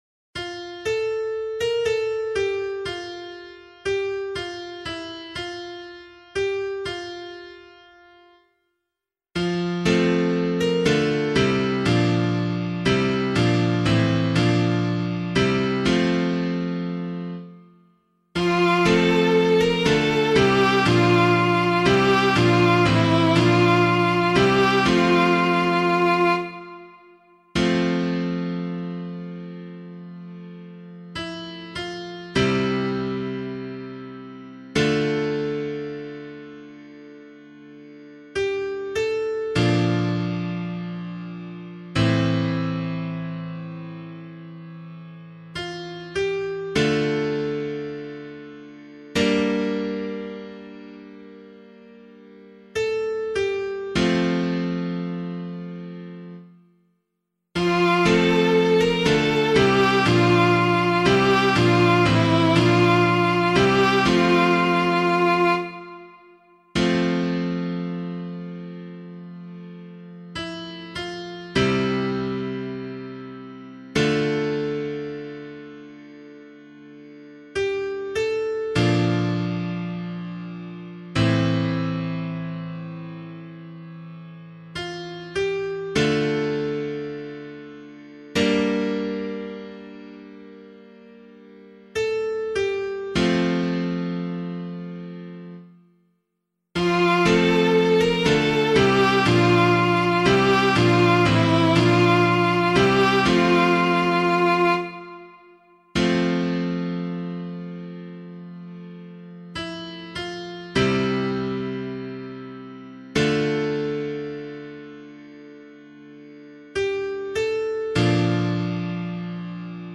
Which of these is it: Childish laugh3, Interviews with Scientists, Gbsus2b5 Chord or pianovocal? pianovocal